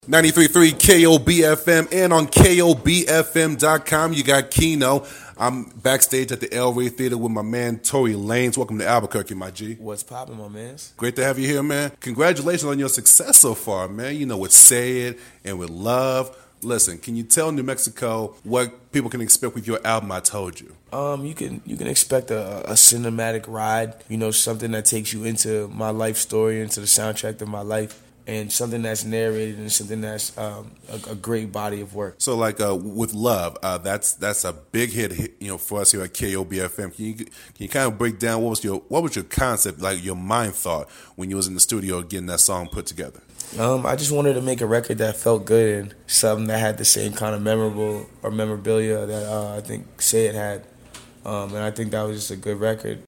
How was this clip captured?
Live from the El Rey Theater 11/01/16